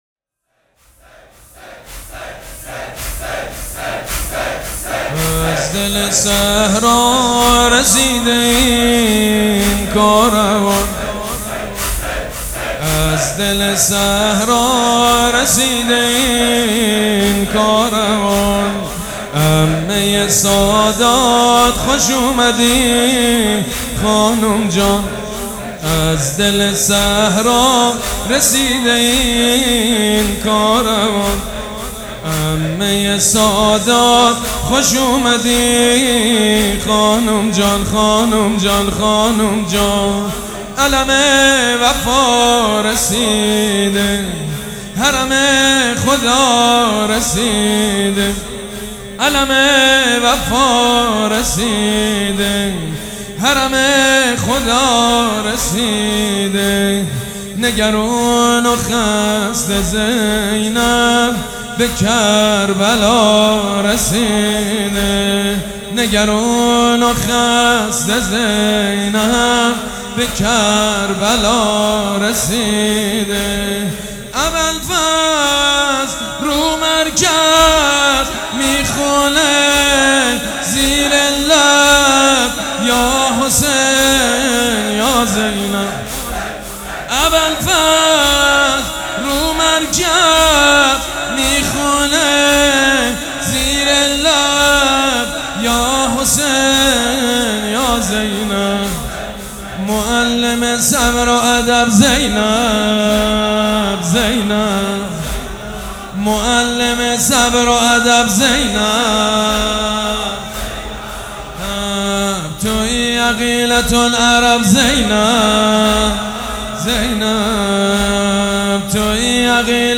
خیمه گاه - شب دوم محرم - ورود کاروان به کربلا - لیست صوت